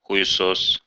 Ääntäminen
Synonyymit мудак козёл гад засранец минетчик миньетчик Ääntäminen Tuntematon aksentti: IPA: [xʊjɪˈsos] Haettu sana löytyi näillä lähdekielillä: venäjä Käännöksiä ei löytynyt valitulle kohdekielelle.